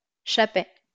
Chapet (French pronunciation: [ʃapɛ]